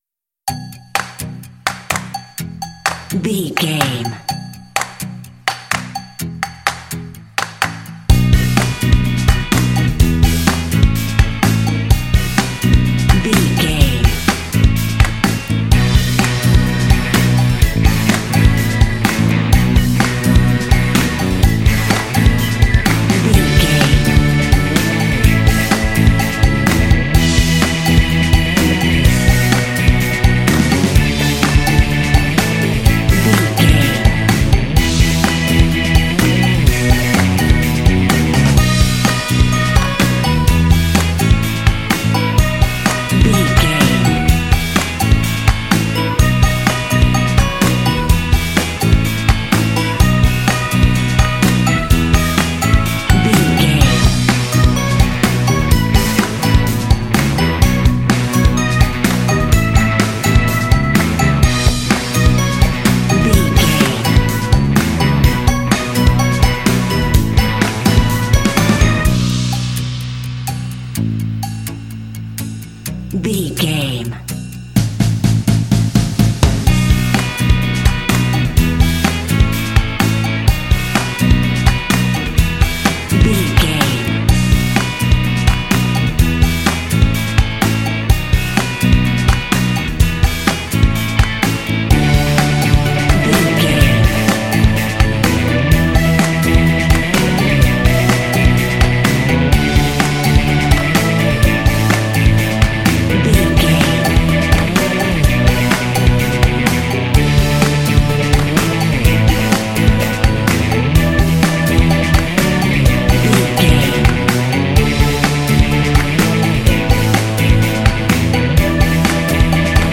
This fun and lighthearted track features a funky organ.
Uplifting
Mixolydian
bouncy
electric guitar
drums
percussion
organ
bass guitar
rock
indie
blues